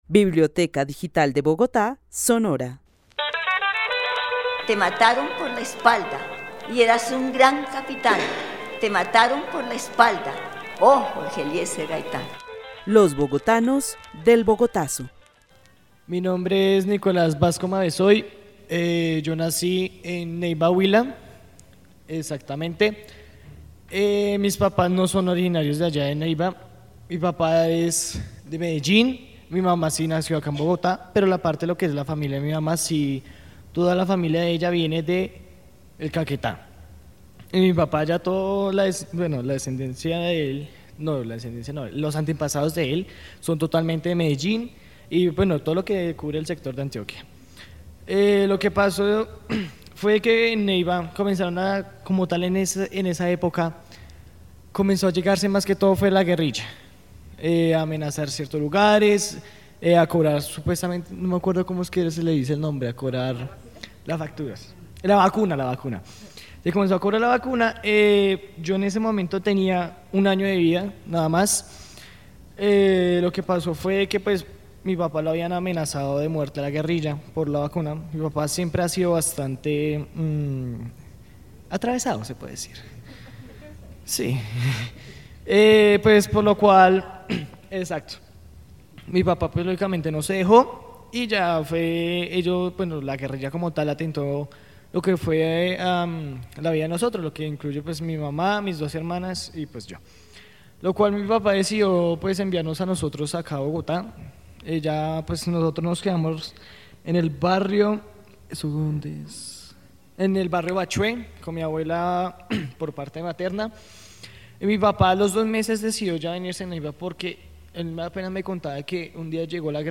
Narración oral sobre la violencia en Neiva y Bogotá. El narrador describe que su familia tuvo que desplazarse desde Neiva a Bogotá durante la década de 2000 por amenazas de muerte de la guerrilla. El testimonio fue grabado en el marco de la actividad "Los bogotanos del Bogotazo" con el club de adultos mayores de la Biblioteca Carlos E. Restrepo.